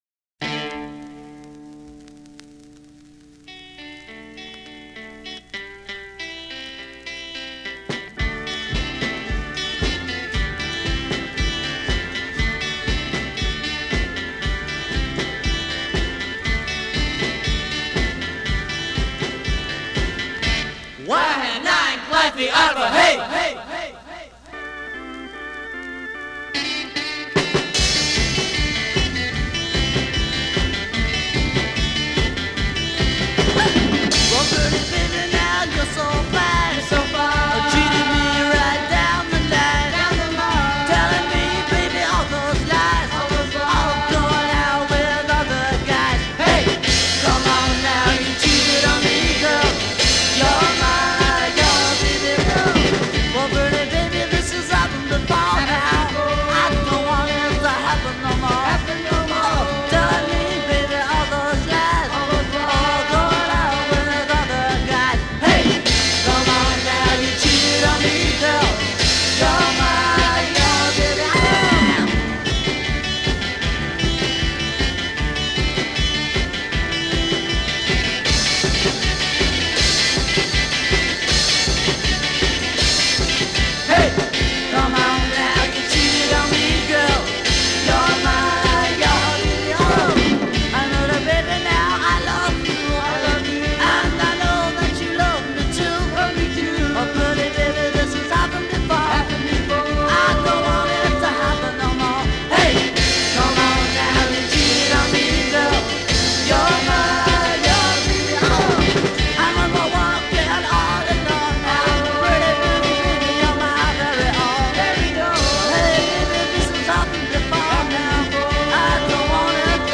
The surface noise sounds like my copy...